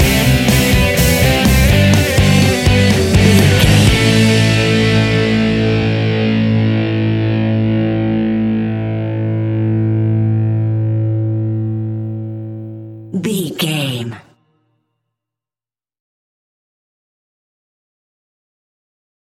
Epic / Action
Fast paced
Aeolian/Minor
hard rock
heavy metal
blues rock
distortion
rock instrumentals
Rock Bass
heavy drums
distorted guitars
hammond organ